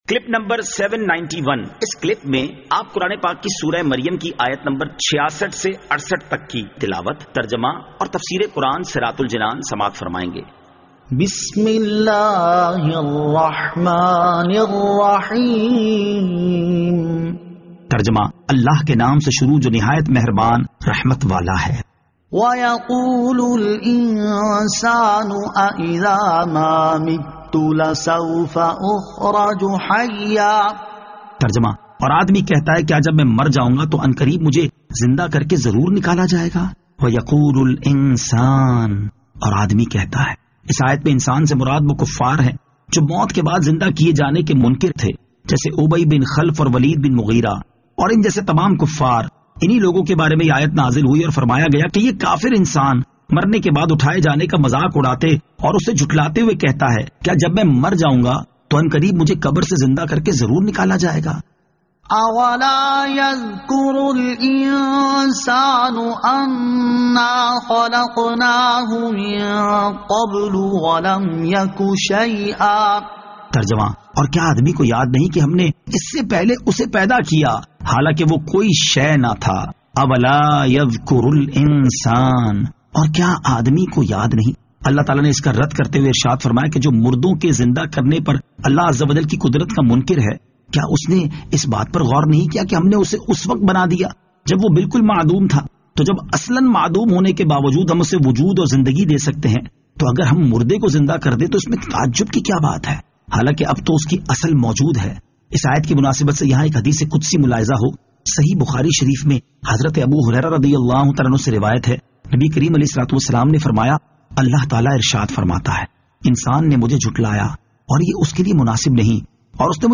Surah Maryam Ayat 66 To 68 Tilawat , Tarjama , Tafseer